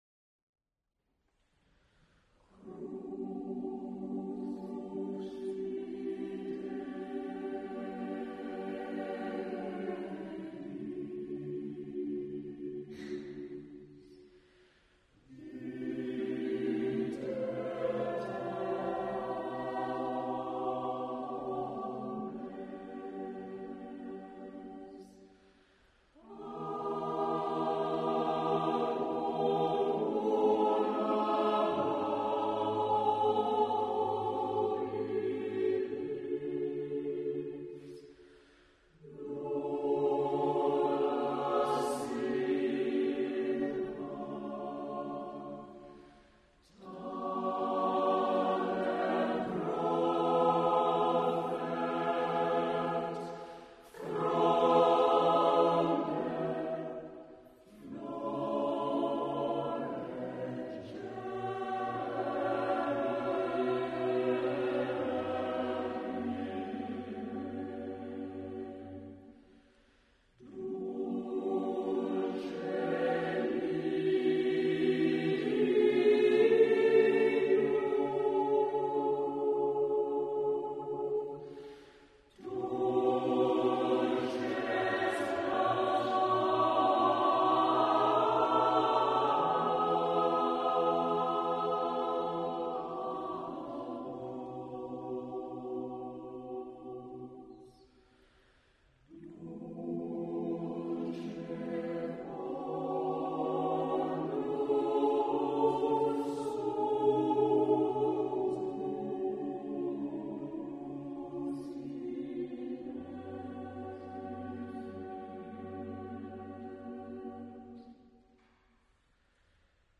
Cherwell Singers Concert March 2007